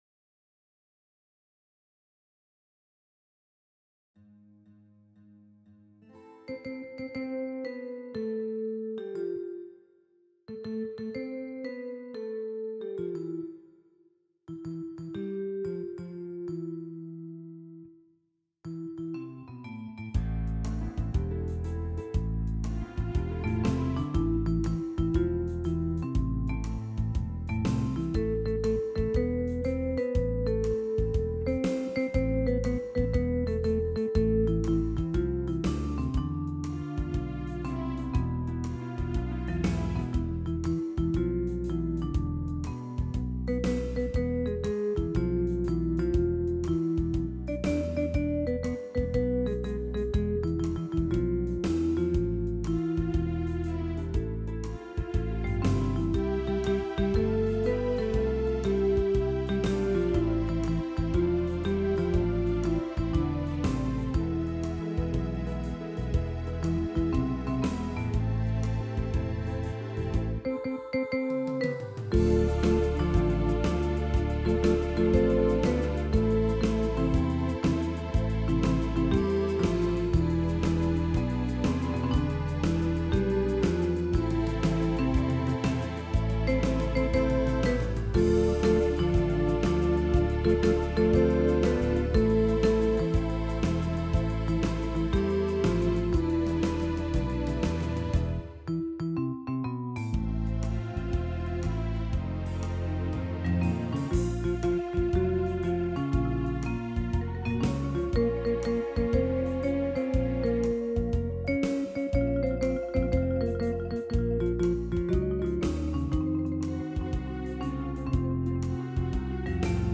As músicas foram executadas com os seguintes teclados:
MÚSICAS EXECUTADAS COM O TECLADO YAMAHA PSR-SX700